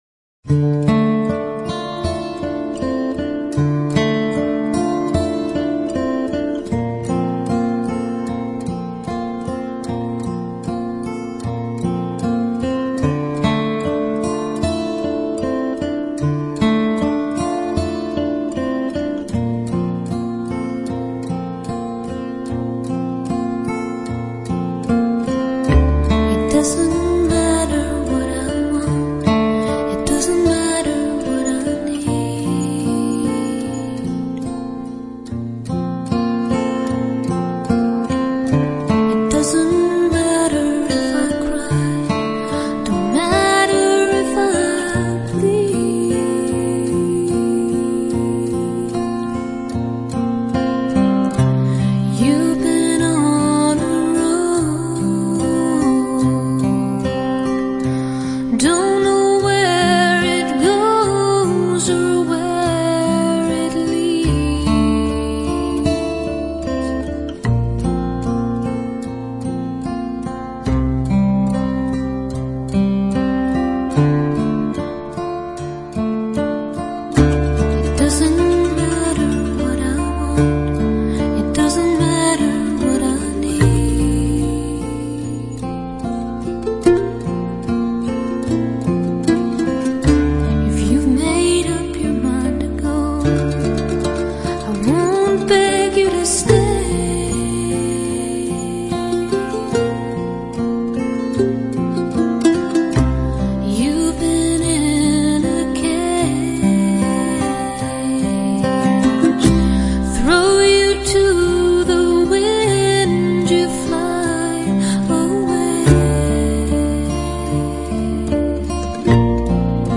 这种音乐有两三部和声，激烈的节奏，不受约束的情感。
这样的声音里，甜美的睡一个午觉，Banjo轻妙， Fiddle光滑，不必担心它们会吵着你。